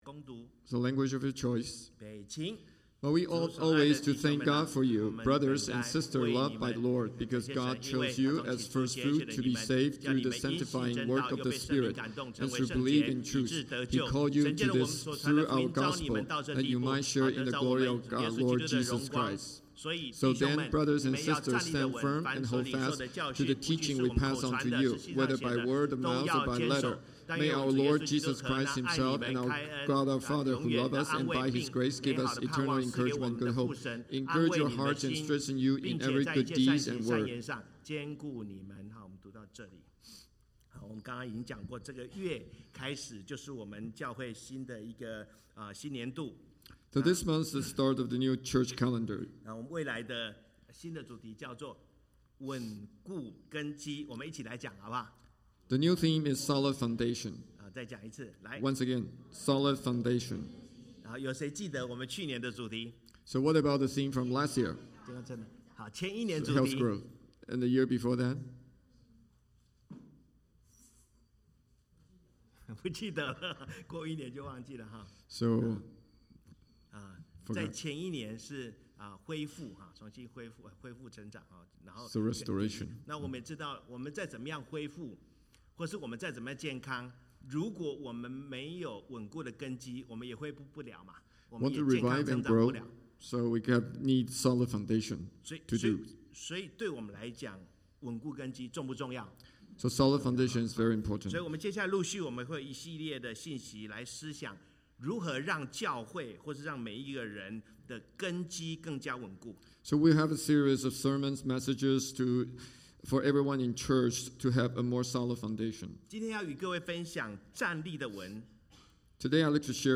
Sermon - CCBCSOC